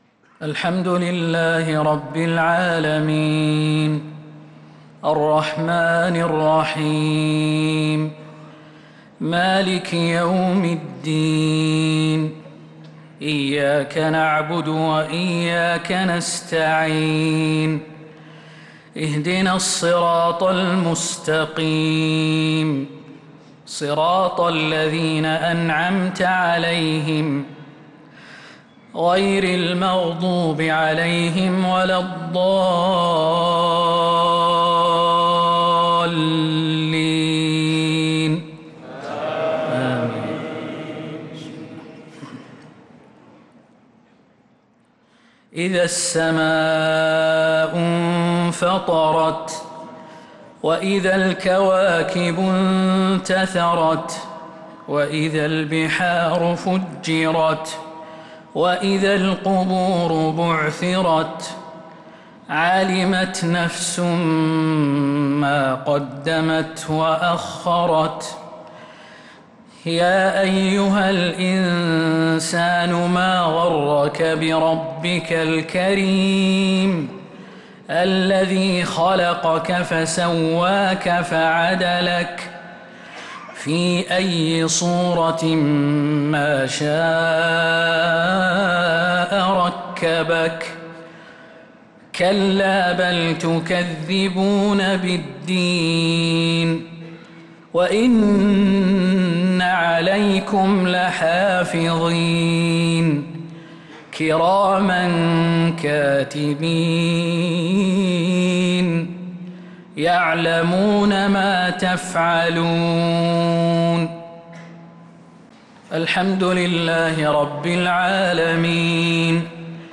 عشاء الخميس 2-7-1446هـ سورة الإنفطار كاملة | isha prayer Surat al-Infitar 2-1-2025 > 1446 🕌 > الفروض - تلاوات الحرمين